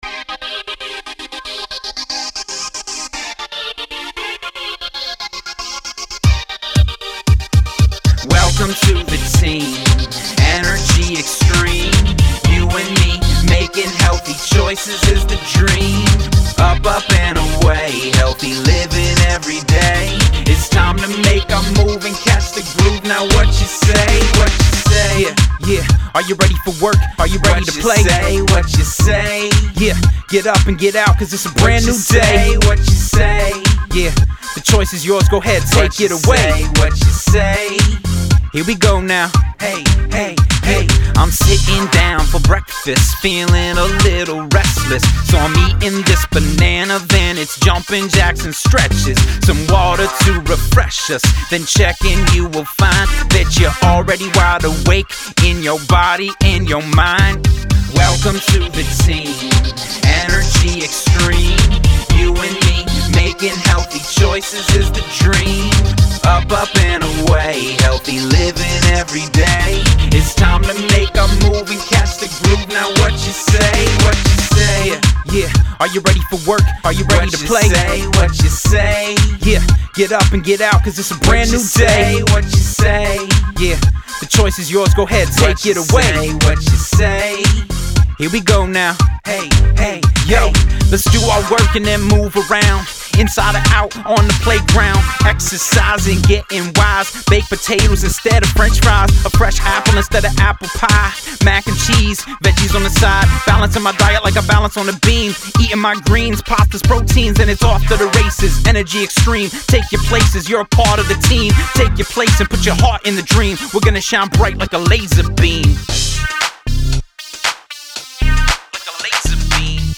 a fun, high energy song